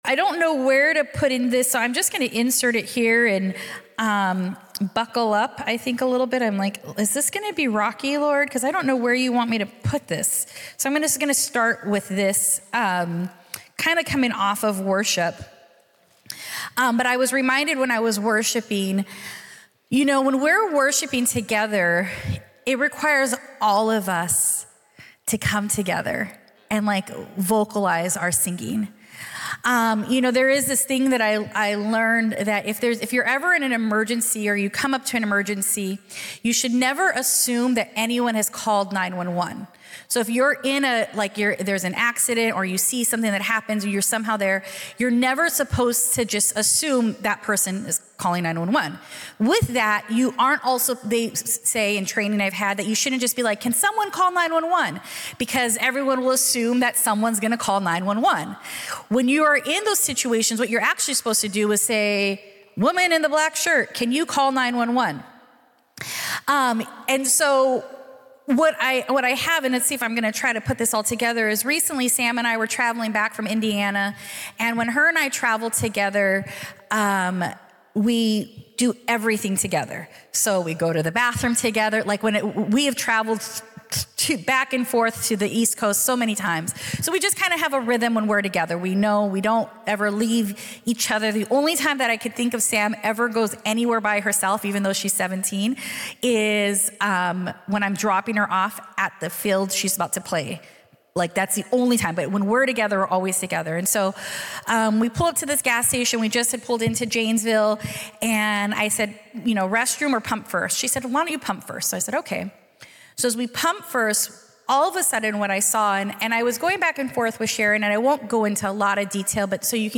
A message from the series "Single."